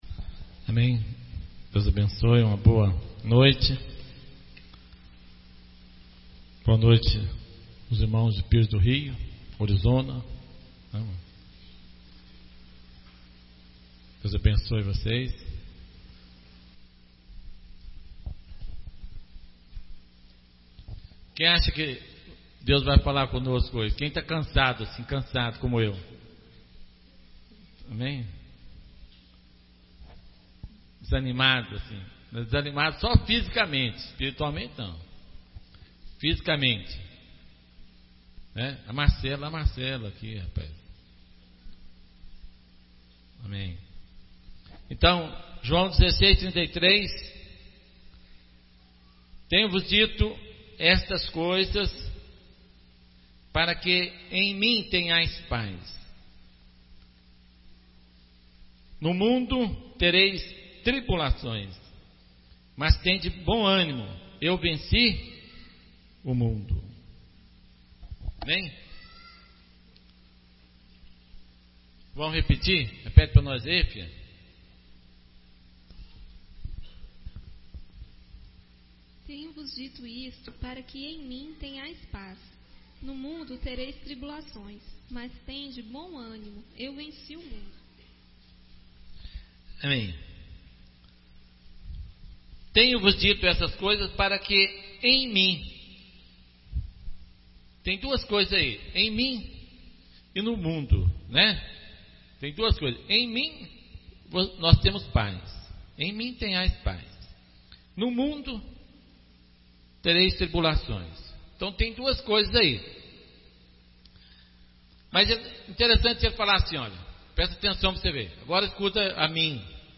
Reunião 2005-07-06 – Vencer o diabo ou ser vencido por ele (tribulação) – quarta.